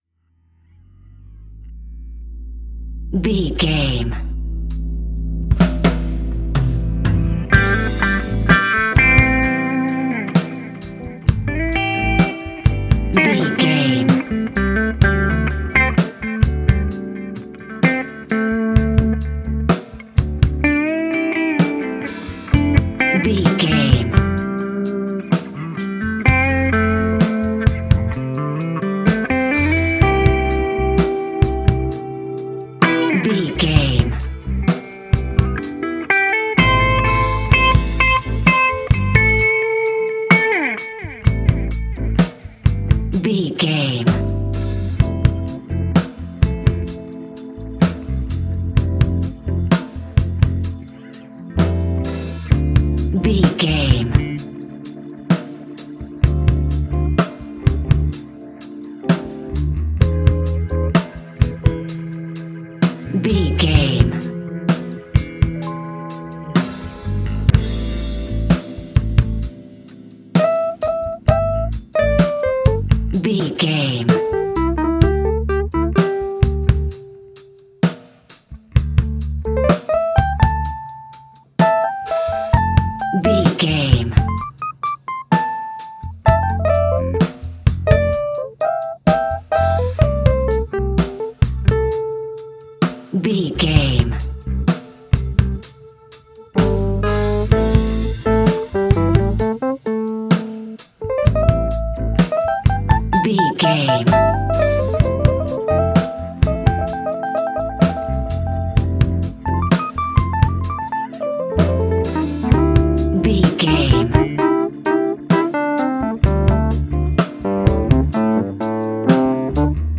Epic / Action
Fast paced
In-crescendo
Uplifting
Ionian/Major
A♭
hip hop
instrumentals